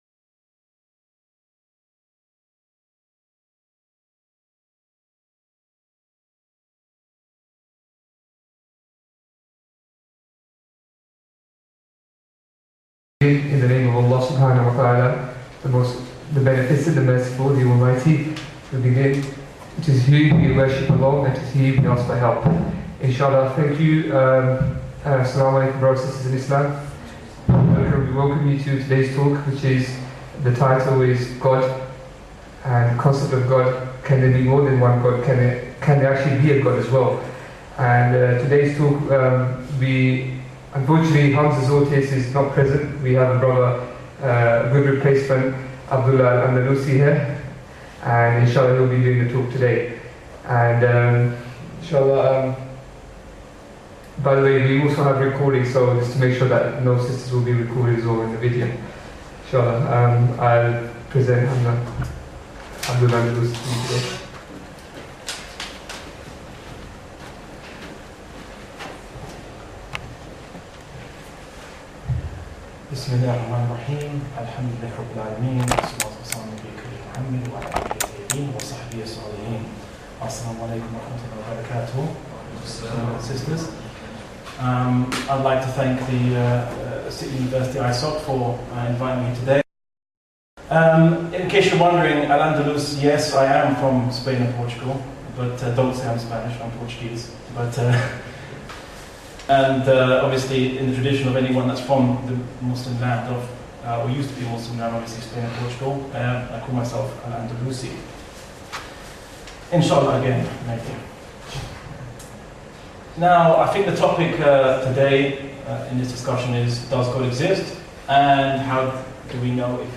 Does God exist？ & How do we know there is only one God？ Lecture at City University London.mp3